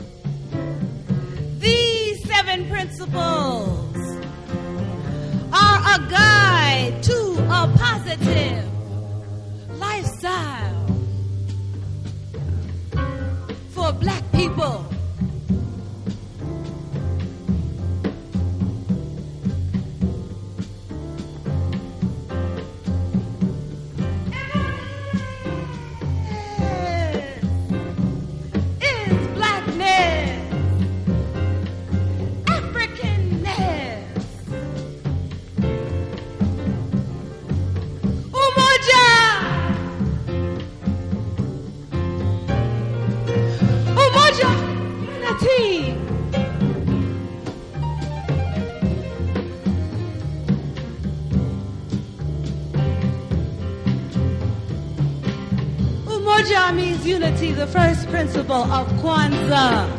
This is post-Coltrane progressive jazz of the highest order!
modal, rhythmic and Afro-centric jazz